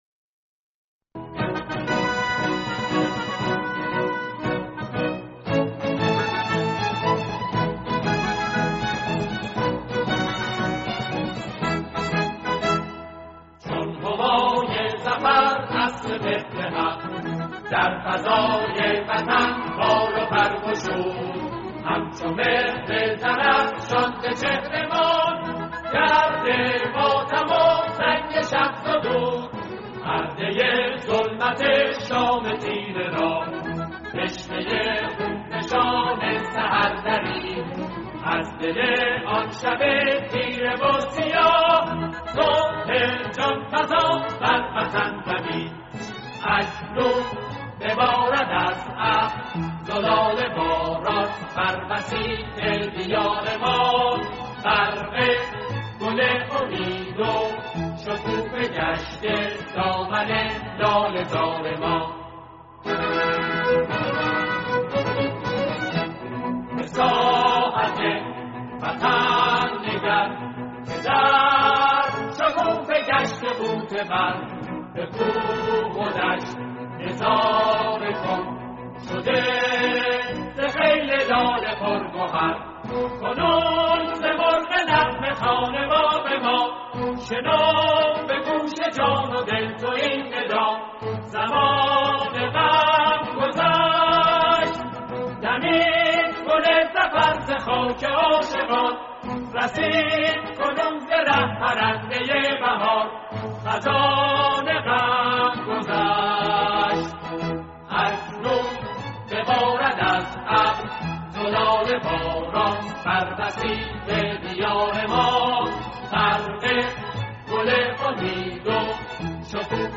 سرود
شیوه اجرا: اركستر